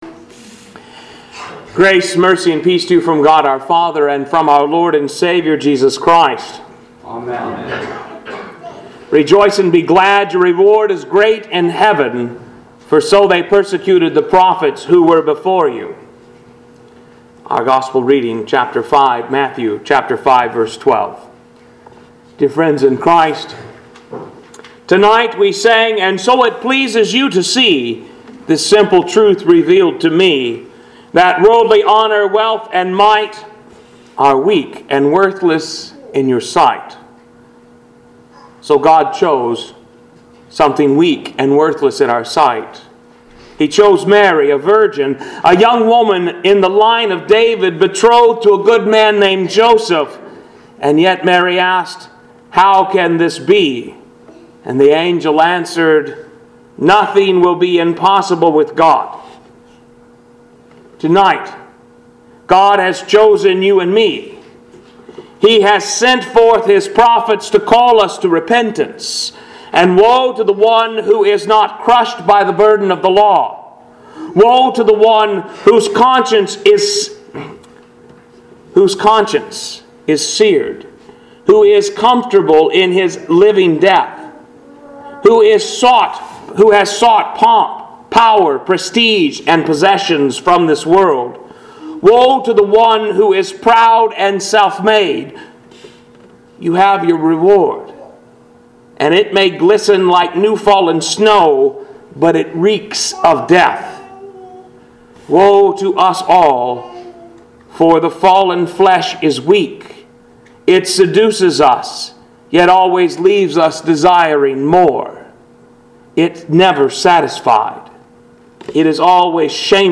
Pilgrim Ev. Lutheran Church - Sermons